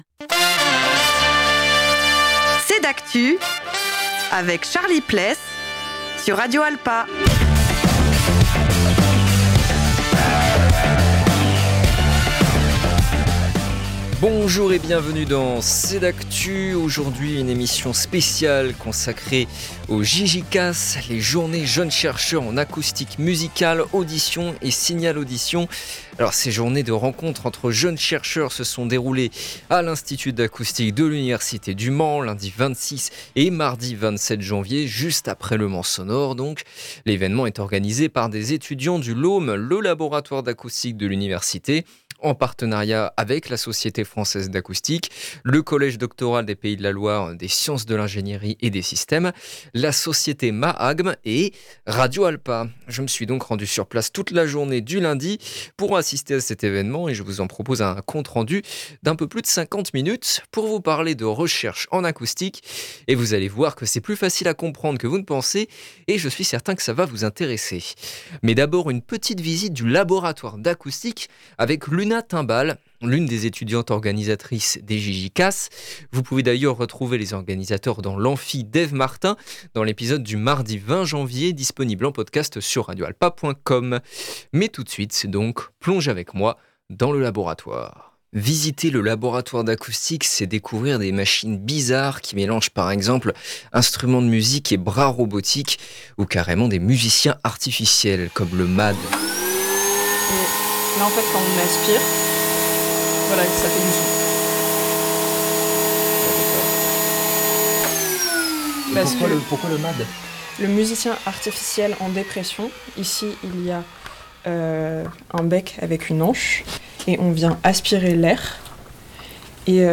Nous nous sommes donc rendu sur place toute la journée du lundi pour assister à cet évènement : nous vous en proposons un compte rendu de 55 minutes, pour vous parler de recherche en acoustique… Et vous allez voir que c’est plus facile à comprendre que vous pensez, et que ça va vous intéresser ! Visitez le laboratoire d’acoustique de l’Université, écoutez les jeunes chercheurs présenter leurs thèses sur la musique, les sons et les odeurs, ou encore le confort auditif des personnes autistes, et découvrez le métier d’archéologue du patrimoine sonore !